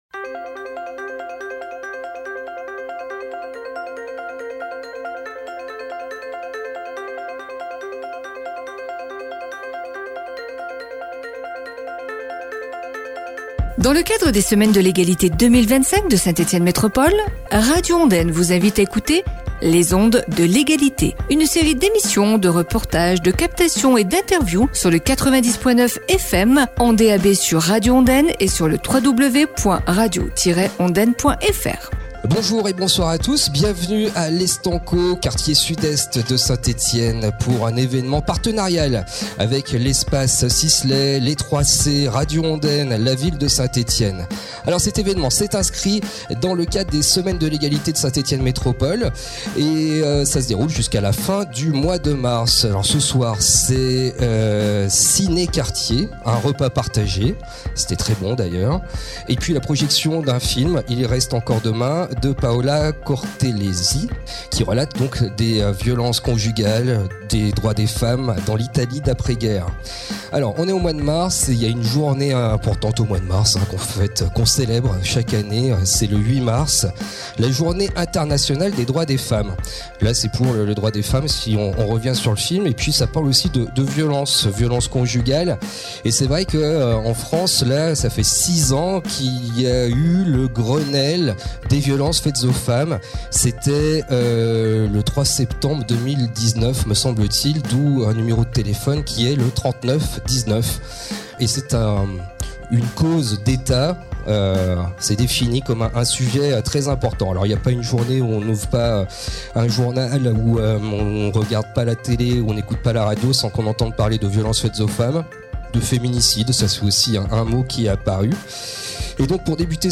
Vendredi 21 Mars, le centre social Alfred Sisley de Saint-Etienne proposait, en partenariat avec Radio Ondaine et la ville de Saint-Etienne, une soirée ciné-débat autour des violences conjugales et faites aux femmes.